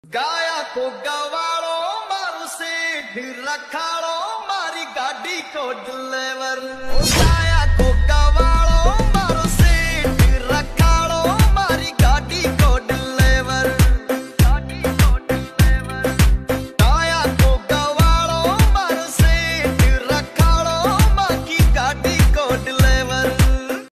Rajasthani songs
• Simple and Lofi sound
• Crisp and clear sound